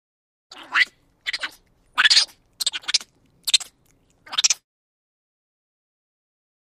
Monkey ( Unknown ) Chatter. Series Of Angry Screams And Chitters. Close Perspective.